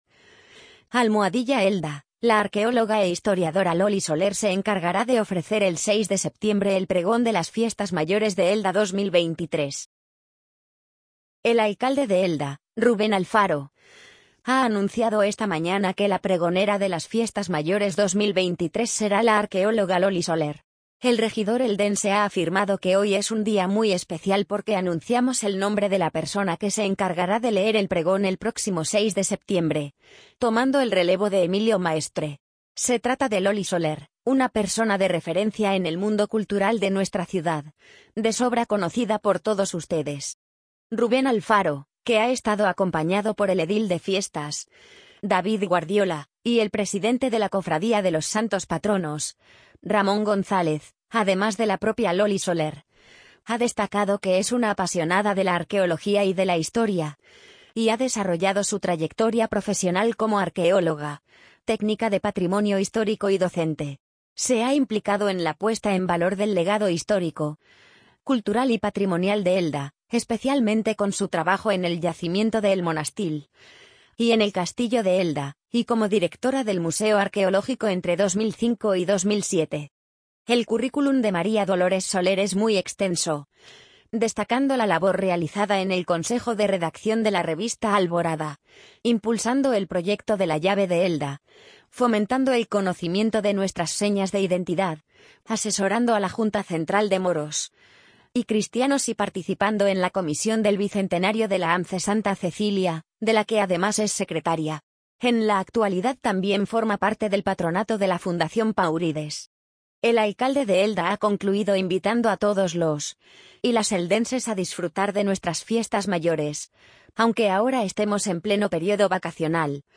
amazon_polly_67783.mp3